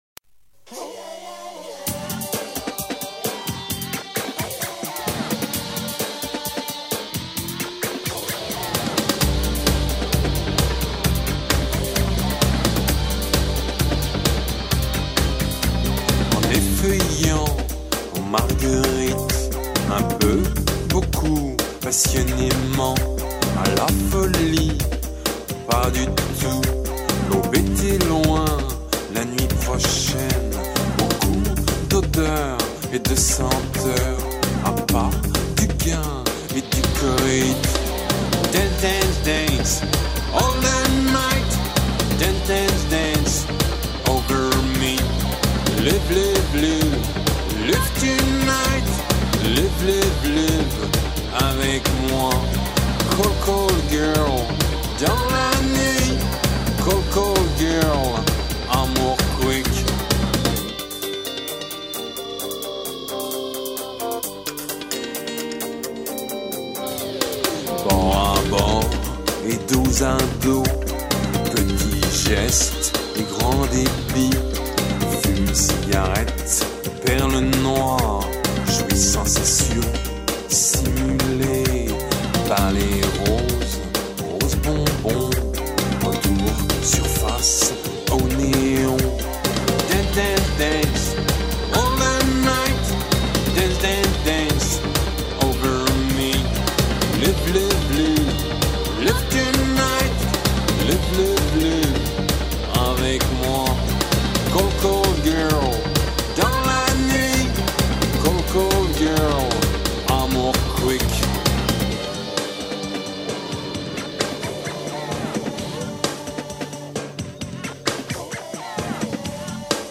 rythm guitar + acoustic guitars